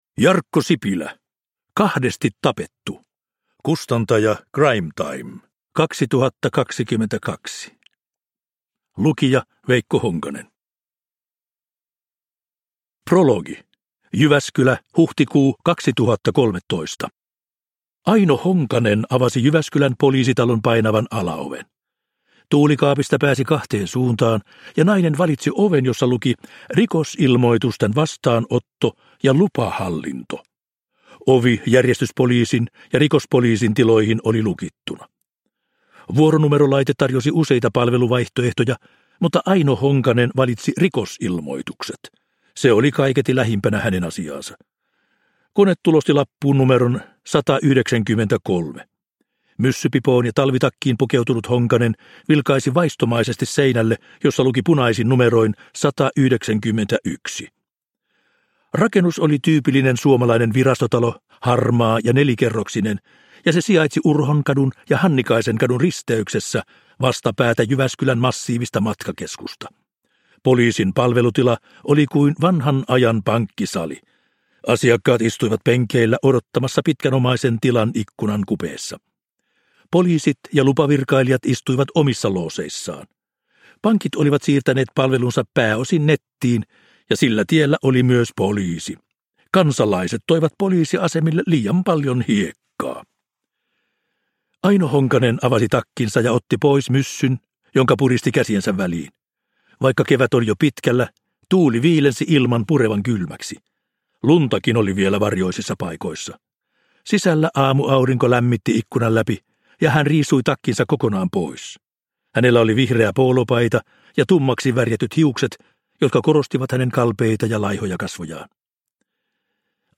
Kahdesti tapettu – Ljudbok – Laddas ner